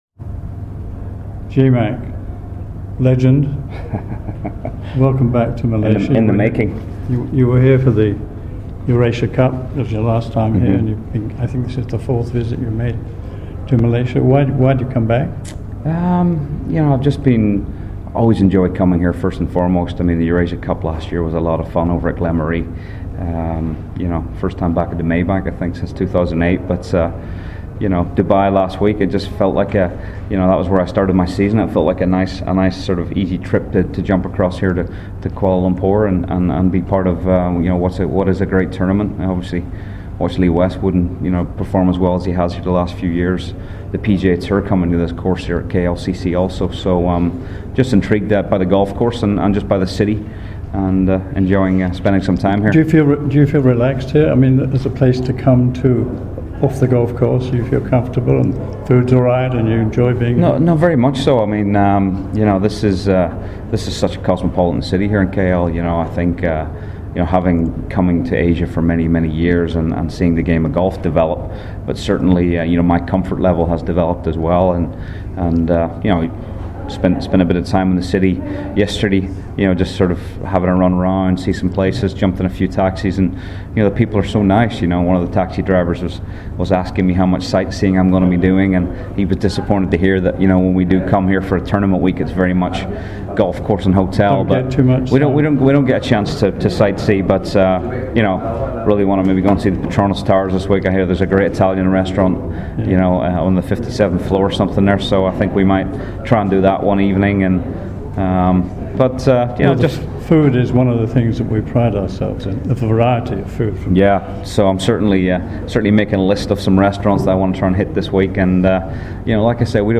at the 2015 Maybank Malaysian Open.